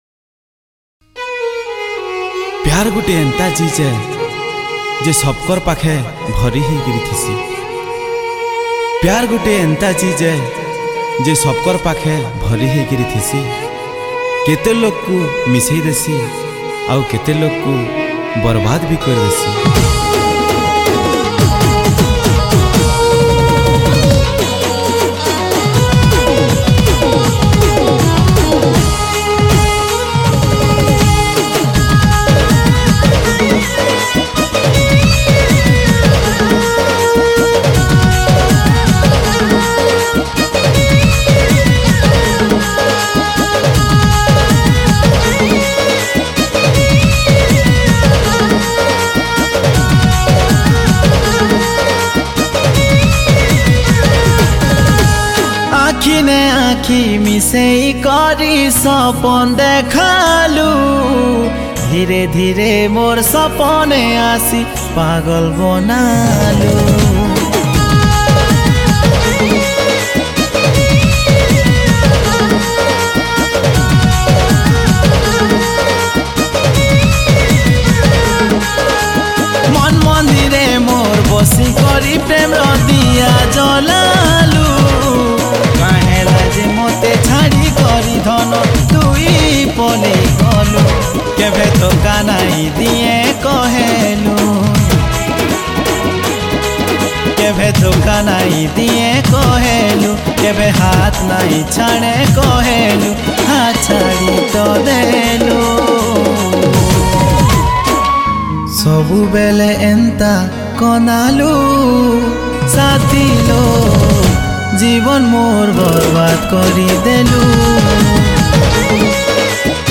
Sambapuri Single Song 2022 Songs Download